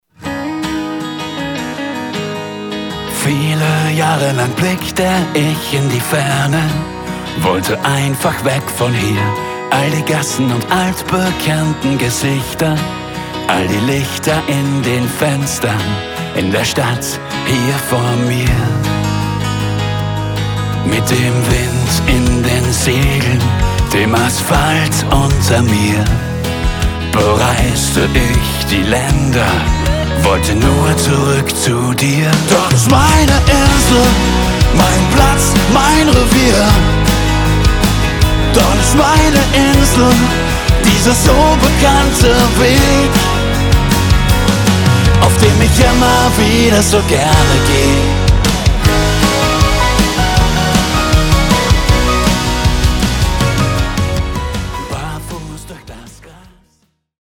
kraftvollen Ballade
Wehmütig. Sehnsüchtig.
Genre: Pop-Rock